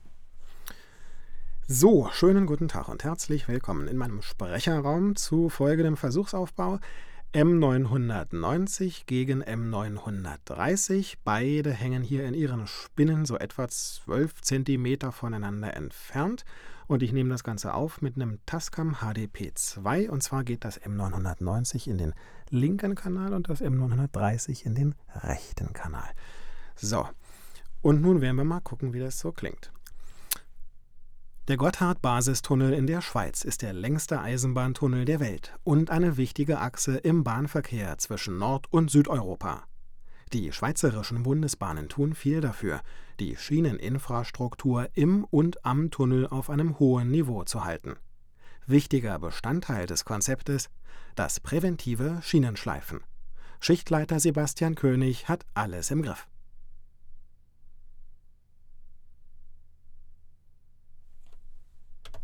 Und dann habe ich hier noch eine MP3-Datei, wo ich mit beiden Mikrofonen den gleichen Text aufgenommen habe. Dabei lief das Signal jeweils durch meine ADT-Module, die ich so eingestellt habe, wie wir das hier in den vergangenen Tagen erarbeitet haben. Also leichte Anhebung bei 60 Hz, leichte Absenkung bei 300 Hz und leichte Erhöhung bei 4 KHz. Beide Mikrofone sind mehrmals abwechselnd zu hören, ich verrate jetzt aber die Reihenfolge nicht.